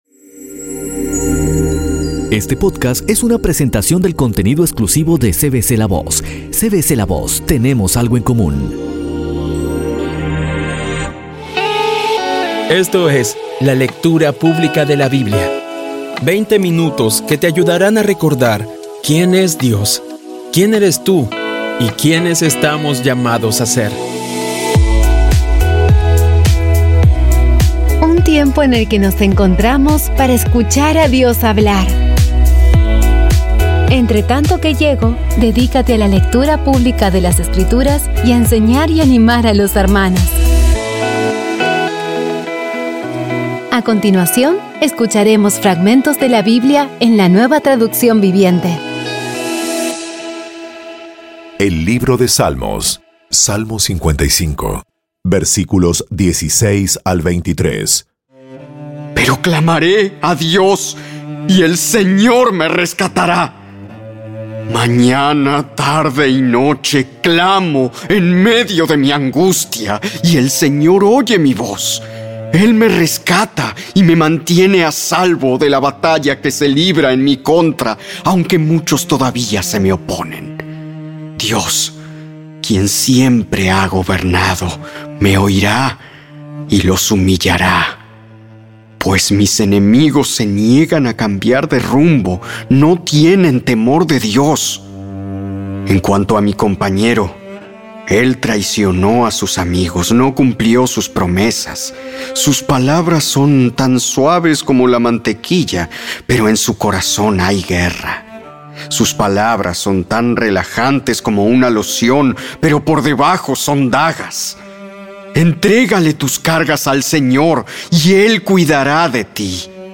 Audio Biblia Dramatizada Episodio 123